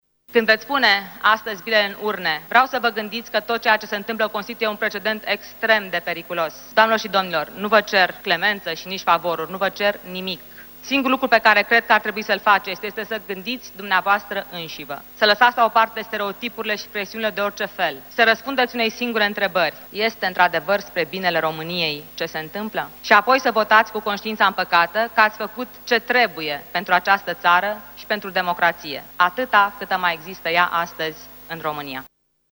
Înainte de vot, Elena Udrea a afirmat în fața deputaților, că este „vânată sistematic” și a cerut modificarea Codului penal și a celui de procedură penală, motivând că arestul preventiv este „o pârghie similară cu tortura la care se apelează prea des”.